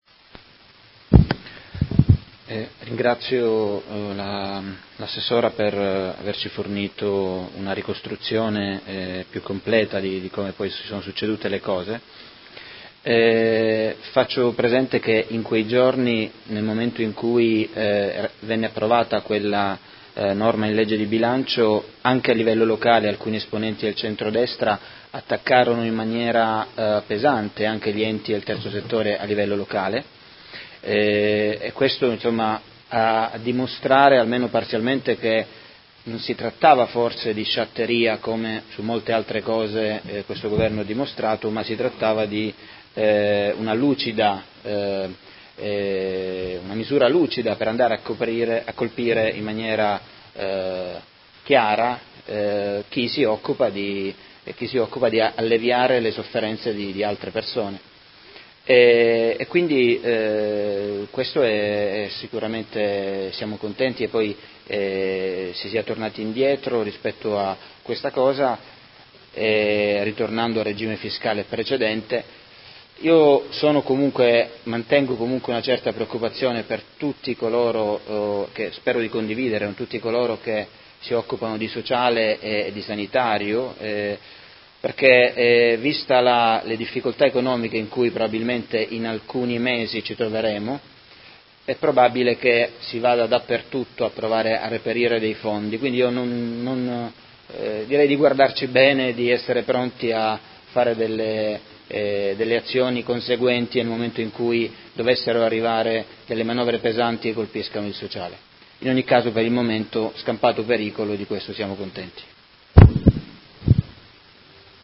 Seduta del 21/03/2019 Replica a risposta Assessora Urbelli. Interrogazione del Consigliere Fasano (PD) avente per oggetto: Quali conseguenze negative sul Terzo Settore modenese in seguito al raddoppio dell’IRES?